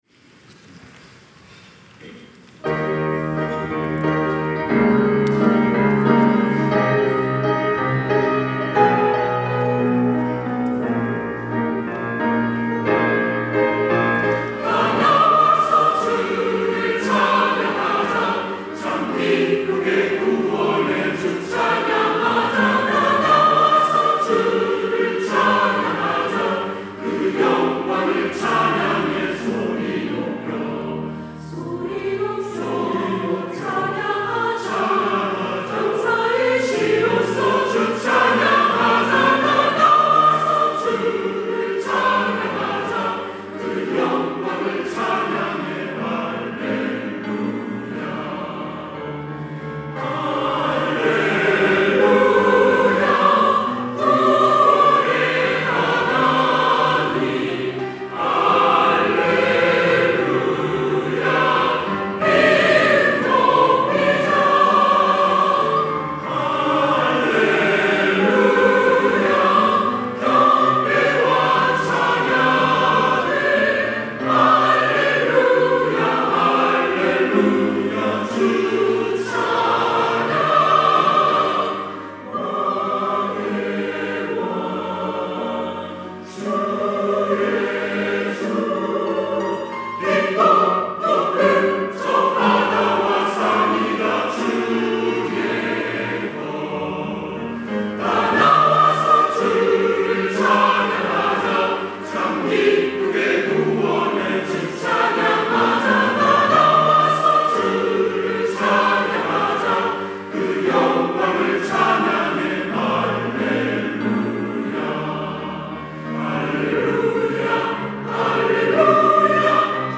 시온(주일1부) - 다 나와서 주를 찬양하자
찬양대